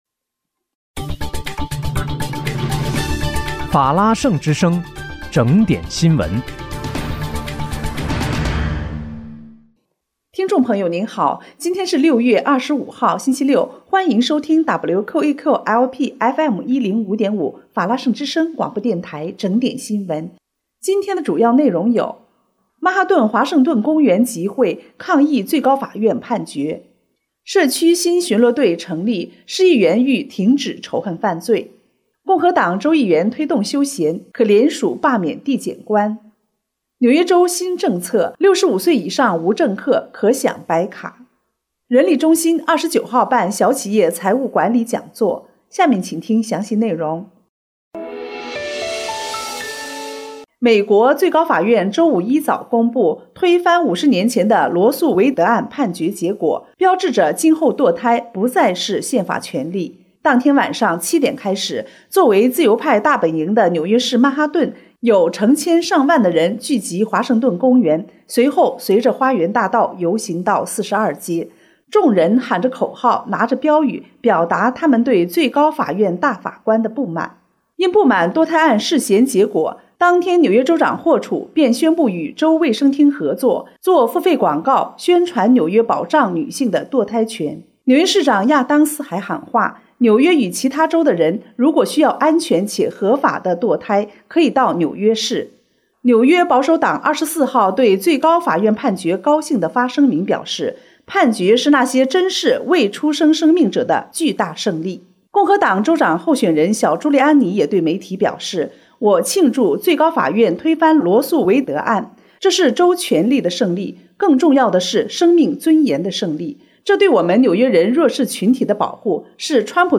6月25日（星期六）纽约整点新闻
听众朋友您好！今天是6月25号，星期六，欢迎收听WQEQ-LP FM105.5法拉盛之声广播电台整点新闻。